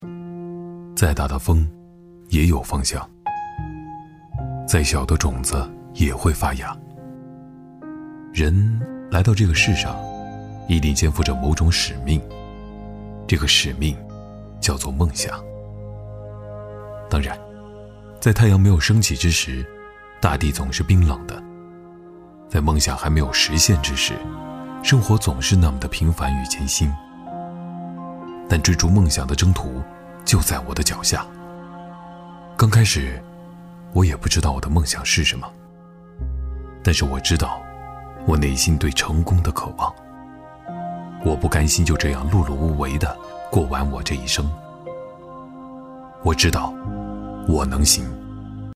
微电影男125号（内心独白
轻松自然 微电影旁白配音